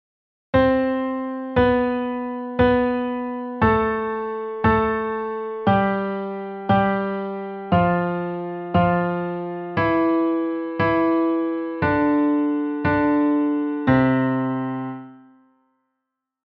音階の二度を意識して、上行と下行の練習
「ミとファ」「シとド」の間は『短二度』 半音 それ以外は『長二度』 全音